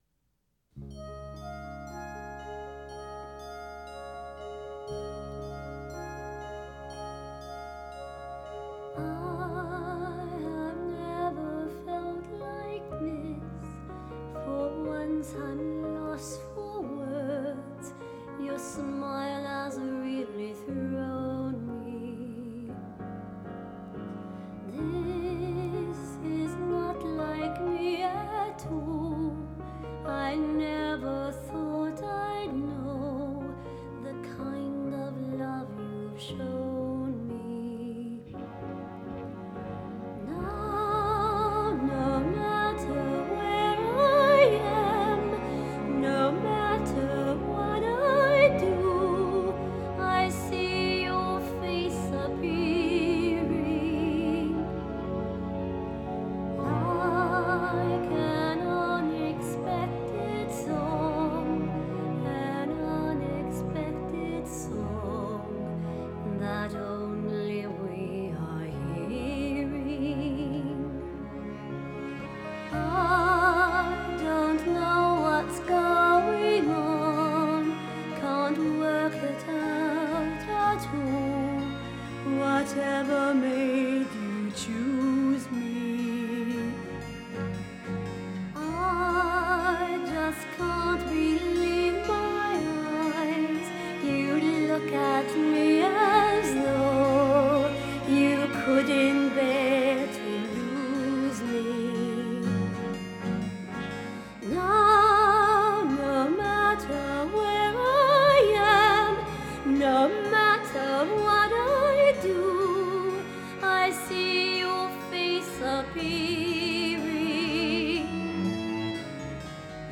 1984   Genre: Musical   Artist